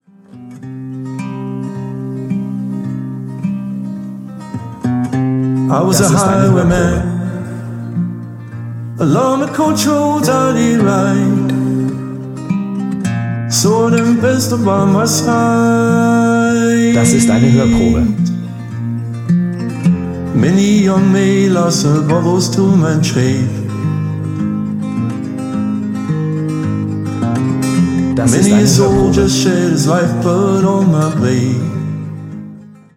Gitarre und Gesang